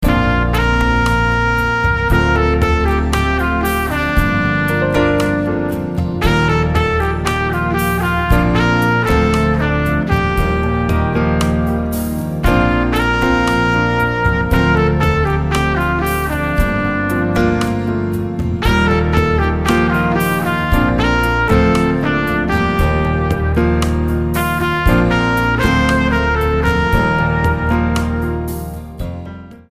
STYLE: Ambient/Meditational